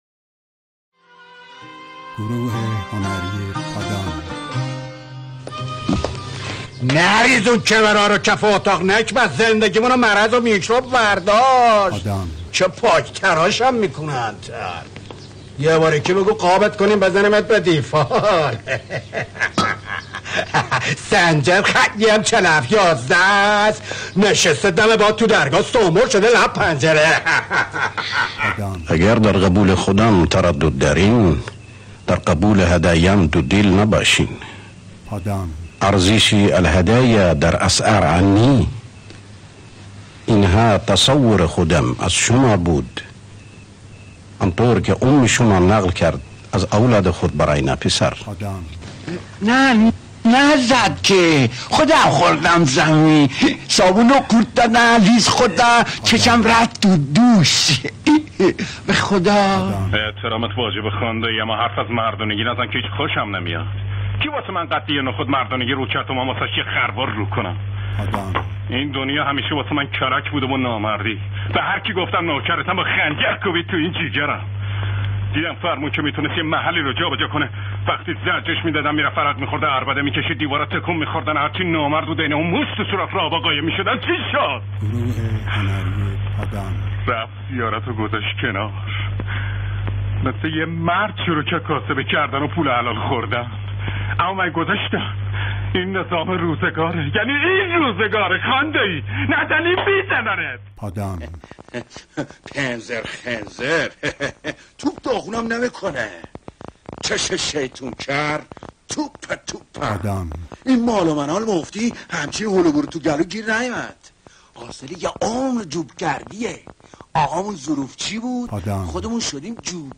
استاد اسماعیلی در تیپ سازی صدا استعداد خارق العاده ای داشت و همین امر موجب شده بود تا در فیلم های مختلف به جای چندین شخصیت صحبت کند.
نمونه کار دوبله منوچهر اسماعیلی
manoochehr-esmaeili-dubing.mp3